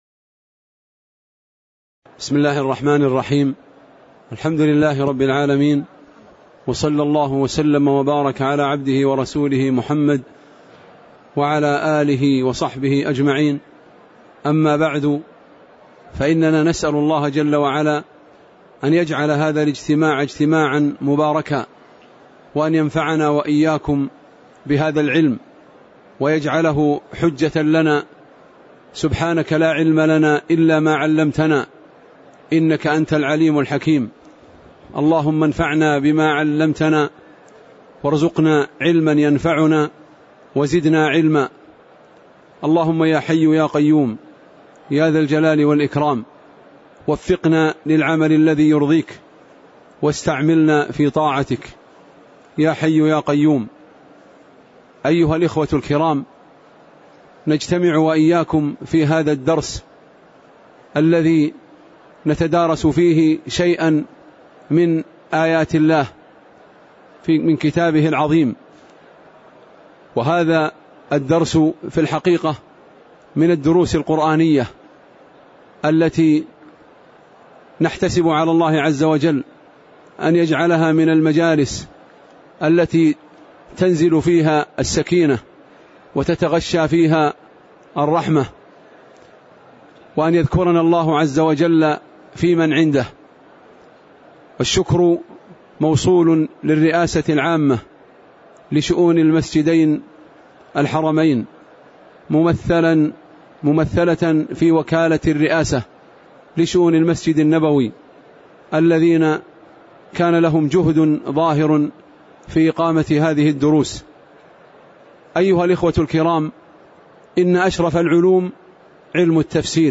تاريخ النشر ١٥ صفر ١٤٣٨ هـ المكان: المسجد النبوي الشيخ